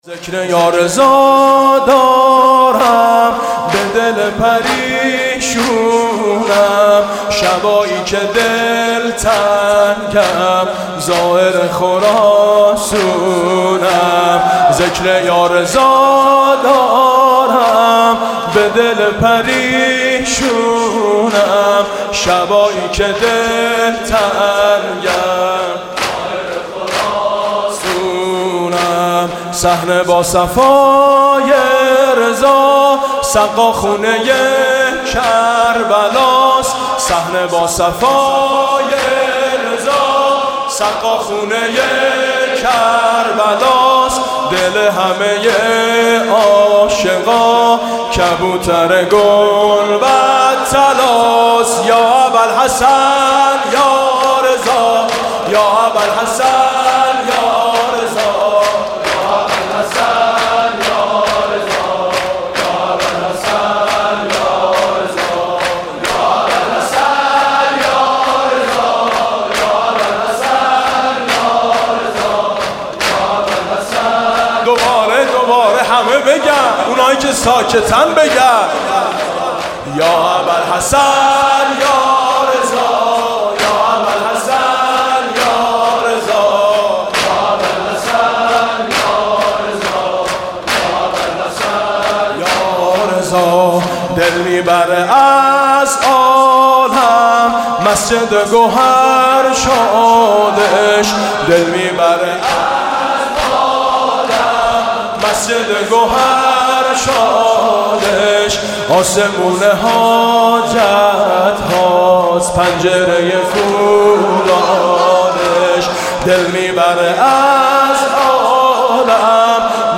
شور: شبایی که دلتنگم زائر خراسونم